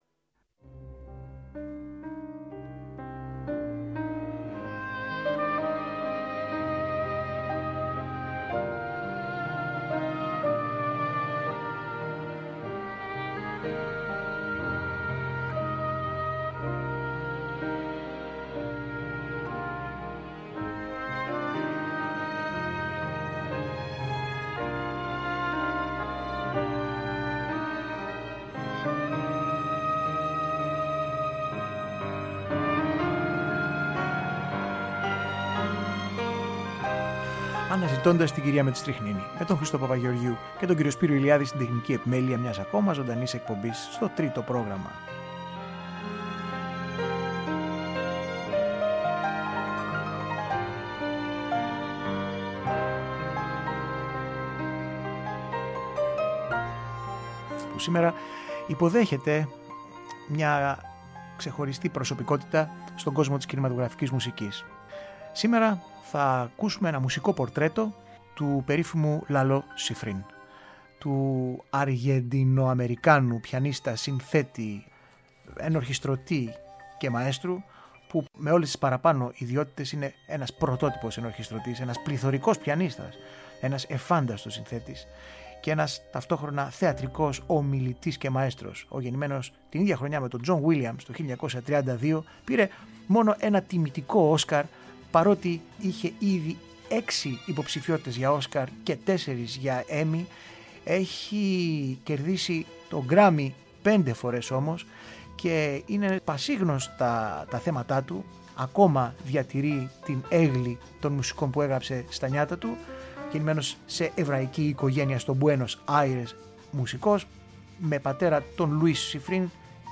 Κινηματογραφικη Μουσικη